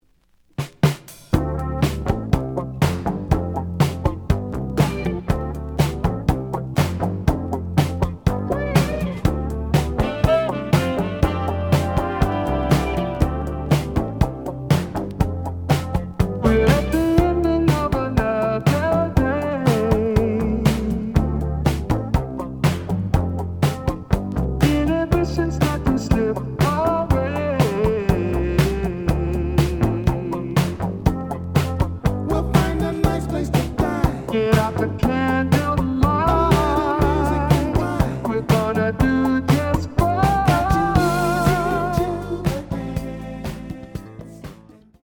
試聴は実際のレコードから録音しています。
The audio sample is recorded from the actual item.
●Genre: Jazz Funk / Soul Jazz